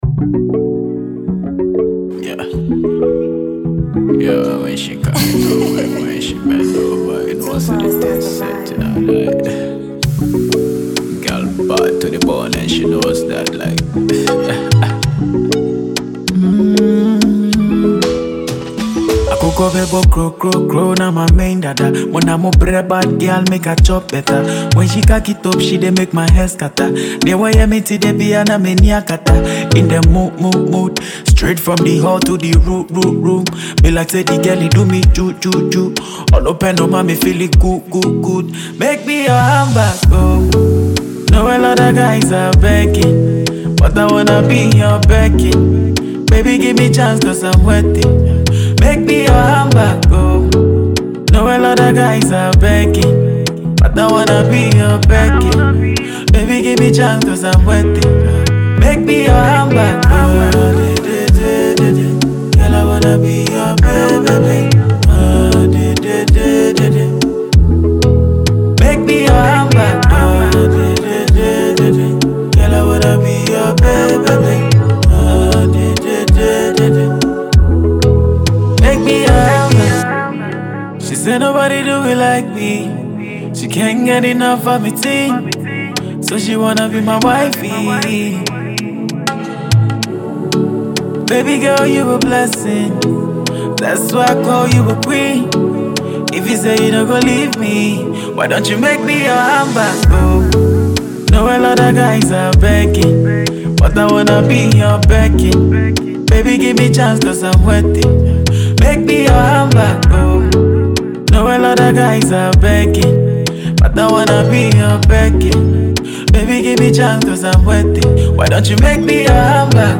Ghana Music Music
rapper